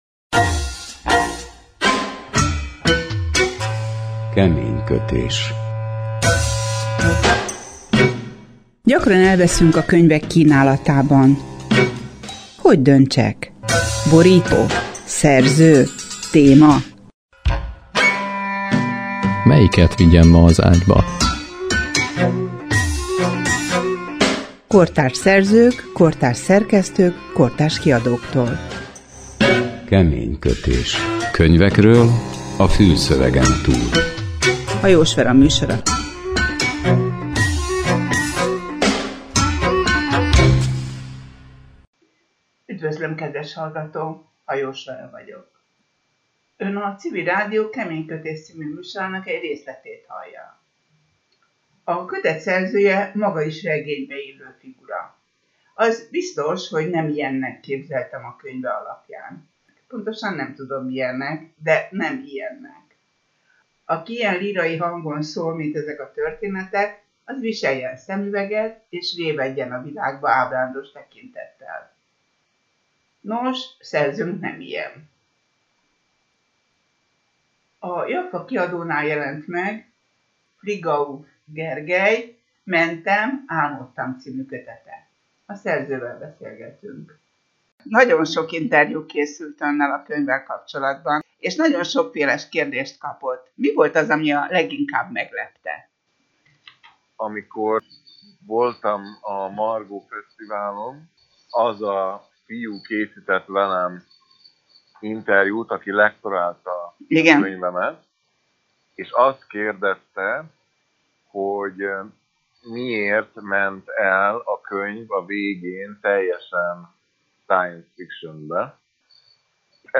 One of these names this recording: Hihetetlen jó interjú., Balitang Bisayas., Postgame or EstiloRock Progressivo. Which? Hihetetlen jó interjú.